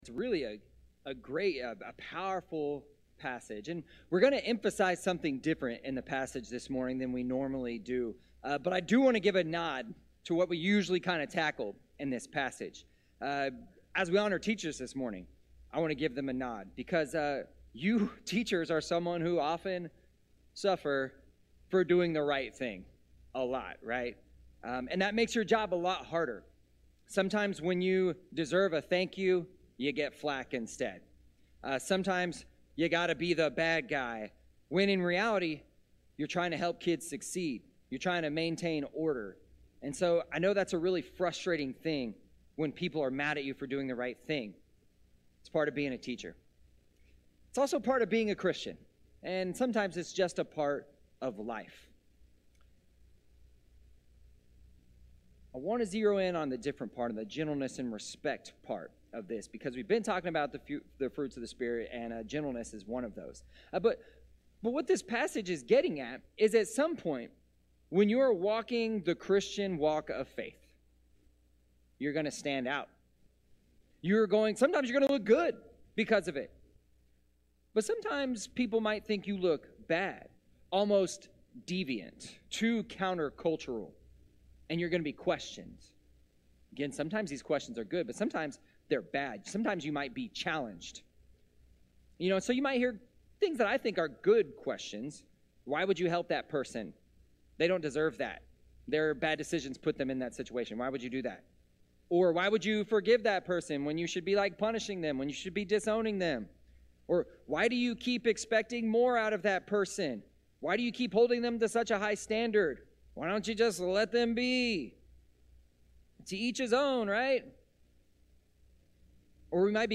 This week's bulletin - 8/13/2023 More from the series: Fruits of the Spirit series ← Back to all sermons